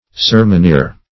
Meaning of sermoneer. sermoneer synonyms, pronunciation, spelling and more from Free Dictionary.
Sermoneer \Ser`mon*eer"\, n.